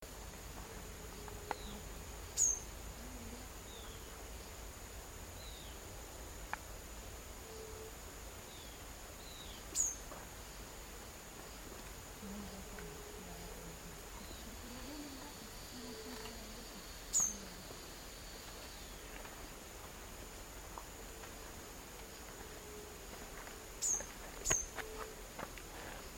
Arañero Silbón (Myiothlypis leucoblephara)
Nombre en inglés: White-rimmed Warbler
Fase de la vida: Adulto
Localidad o área protegida: Parque Nacional El Palmar
Condición: Silvestre
Certeza: Observada, Vocalización Grabada